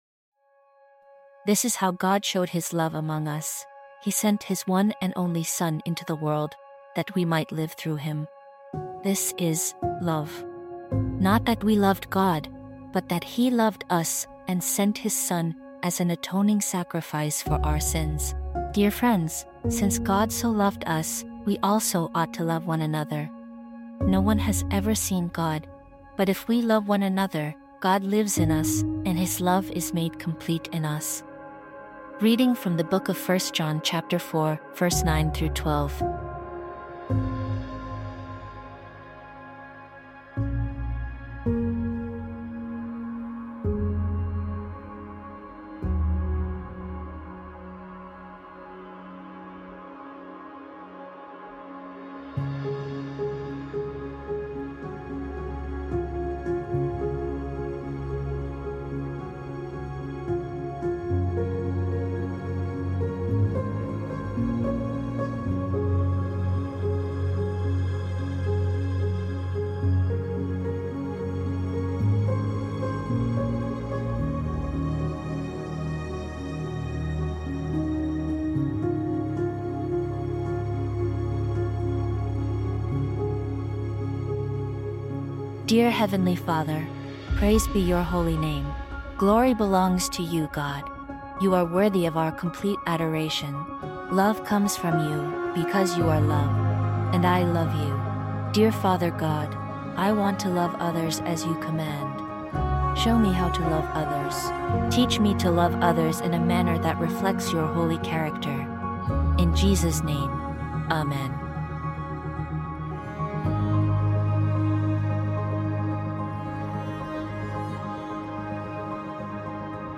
This episode contains a series of prayers.